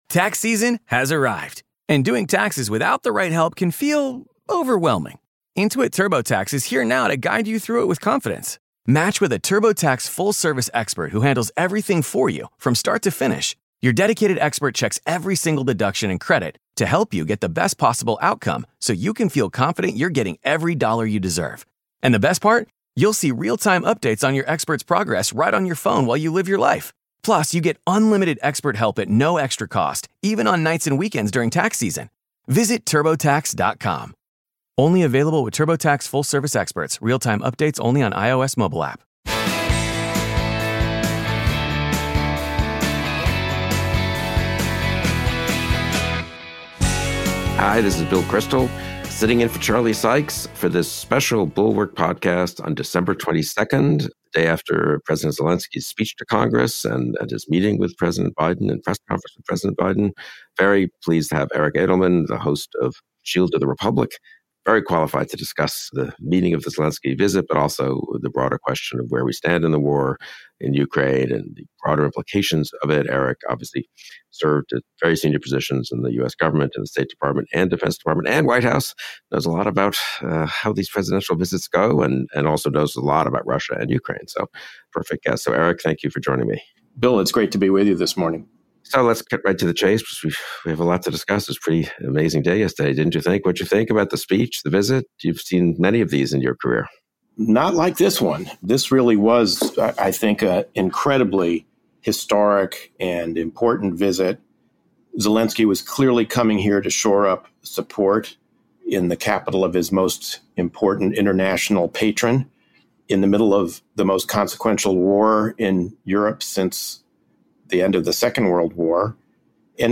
Zelensky didn't hit a wrong note, and he embodied the Ukrainian people's will to freedom on the floor of the US Congress. Plus, from a realpolitik perspective, the degradation of Russian military power via US aid to Ukraine is a bargain. Amb. Eric Edelman joins guest host Bill Kristol today